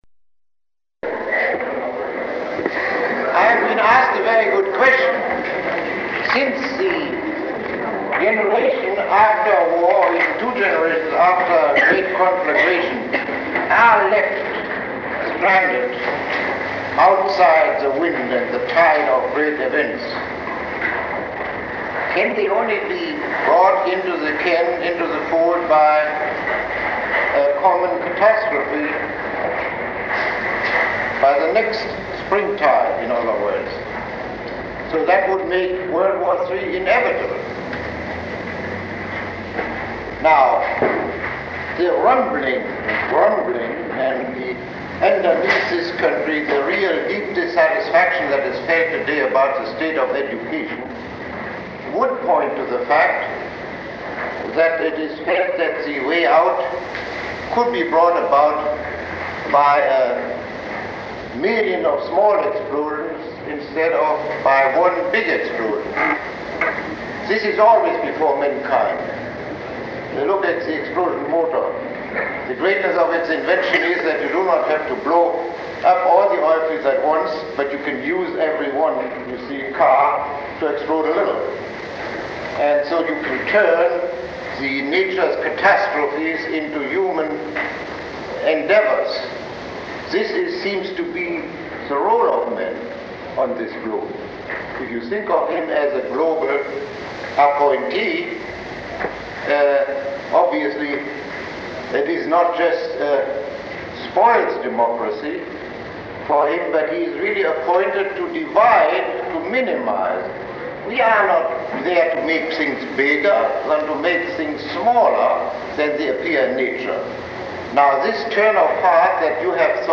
Lecture 14